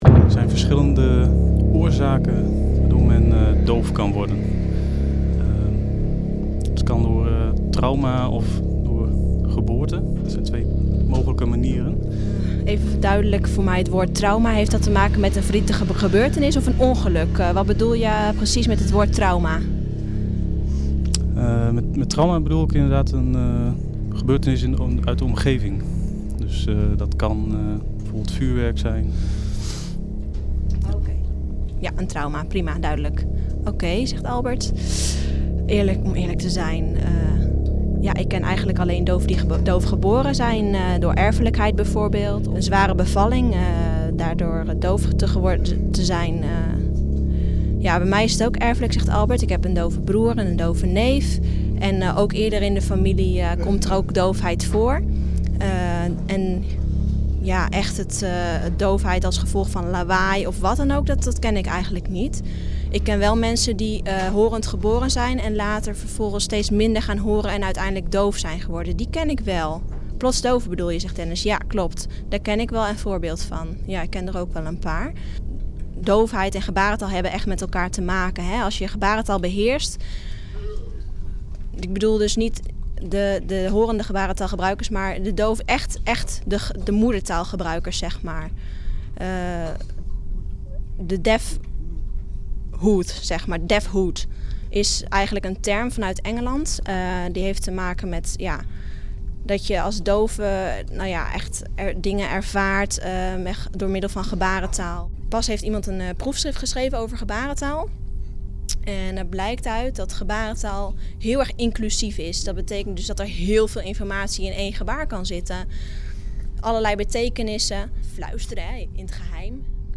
Audio portrait on Deafness and music for Edge 2.0 radio, with a translator acting as an intermediator between the hearing interviewer and the deaf interviewees.